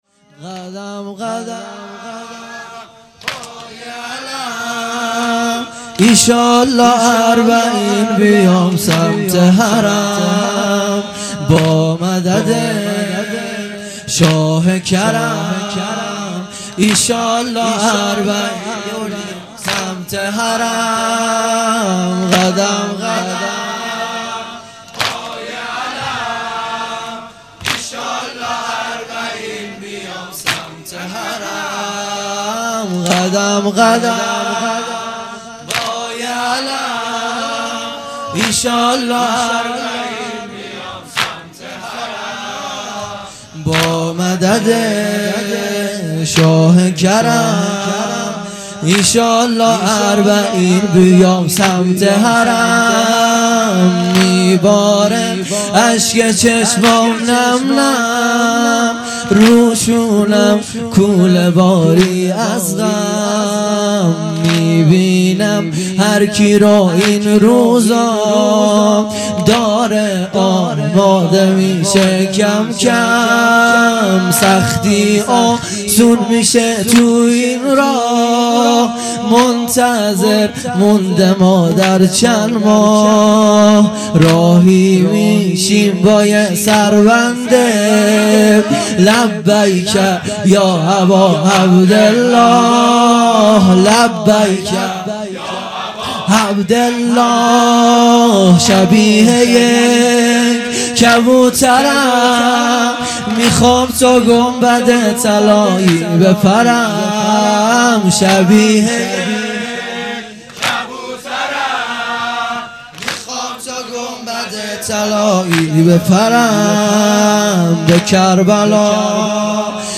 هیئت مکتب الزهرا(س)دارالعباده یزد - واحد تند | قدم قدم با یه علم مداح
محرم الحرام ۱۴۴۱ ، ۱۳۹۸ شب هفتم